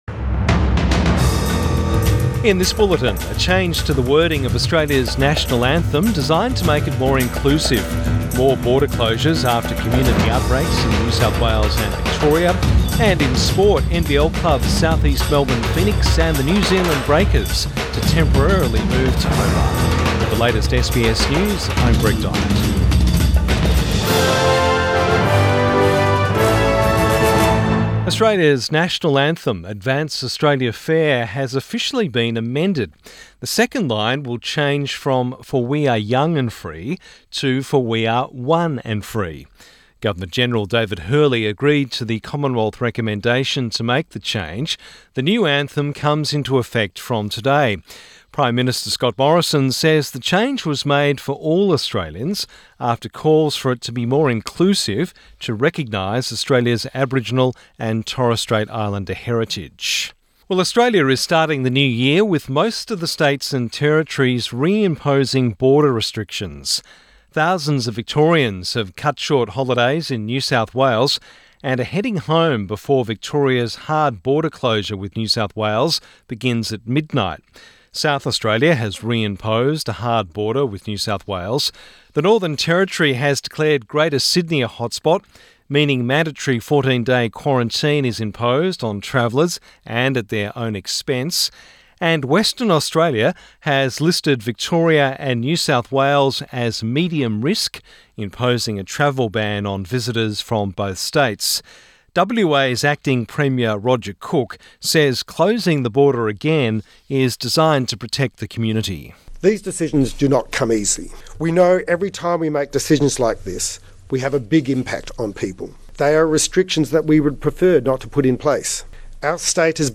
AM bulletin 1 January 2021